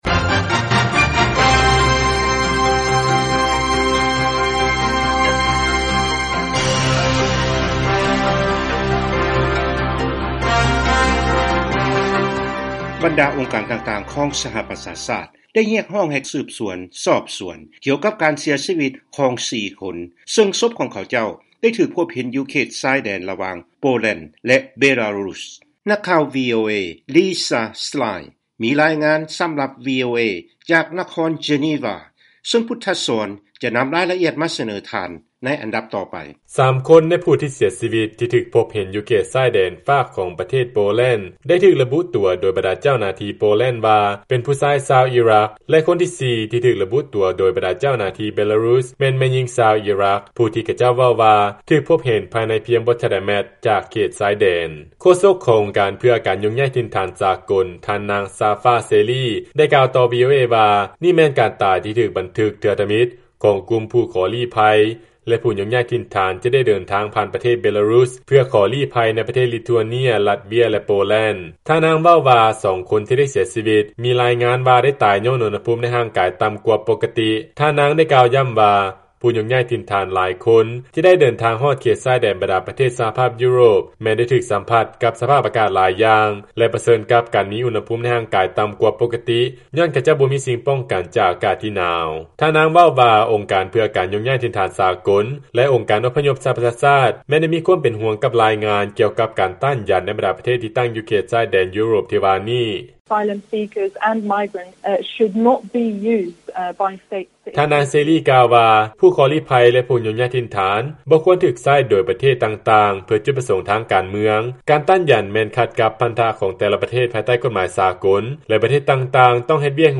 ຟັງລາຍງານ ຜູ້ຍົກຍ້າຍຖິ່ນຖານ 4 ຄົນຖືກພົບເຫັນເສຍຊີວິດ ຢູ່ຕາມເສັ້ນທາງ ລະຫວ່າງ ເຂດຊາຍແດນ ໂປແລນ ແລະ ເບລາຣຸສ